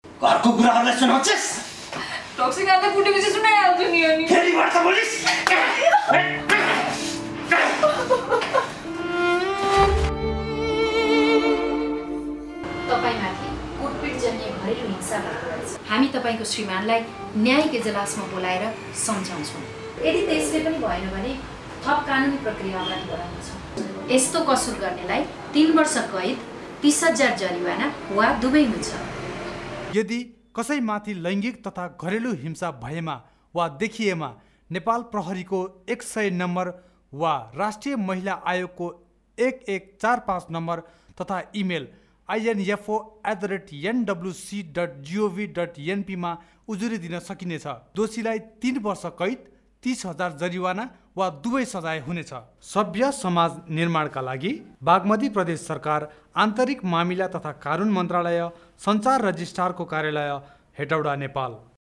लैंगिक हिंसा सम्बन्धी रेडियोमा प्रसारण गर्ने सामग्री